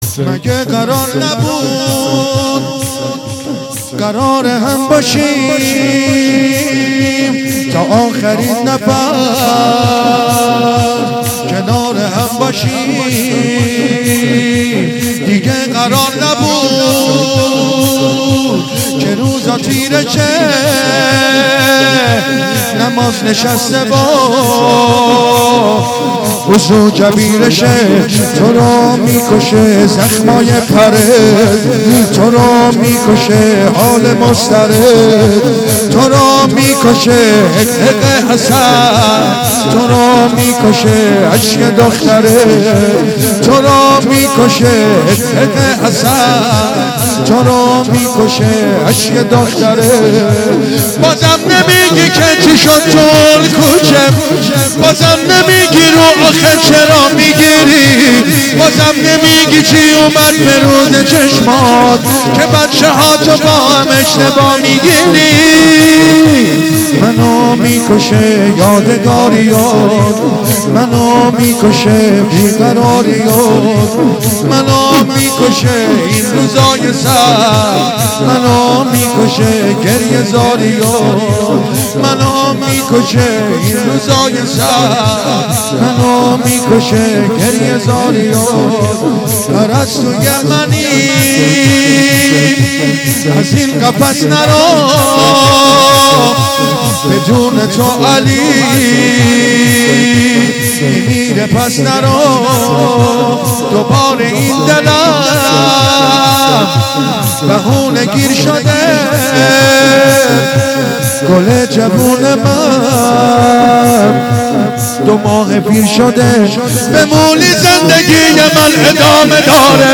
جلسه هفتگی دوشنبه ٢۵ دی ماه ١٣٩۶